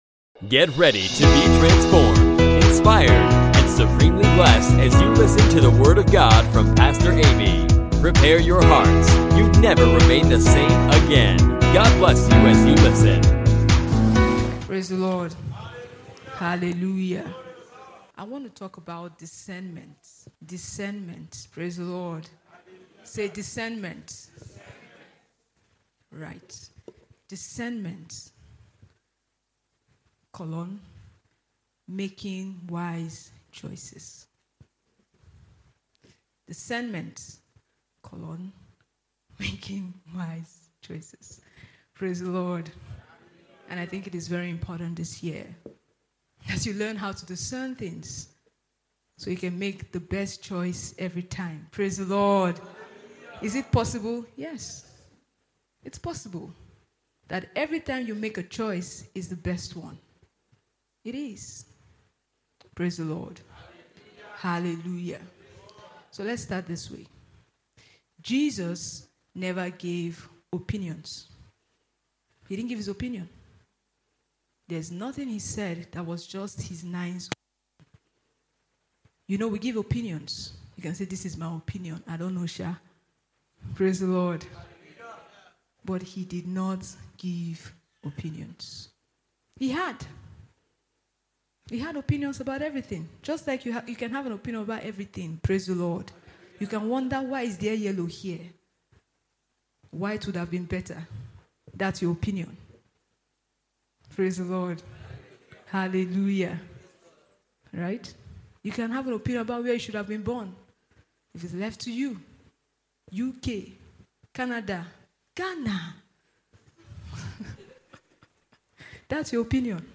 Pastor teaches on the discernment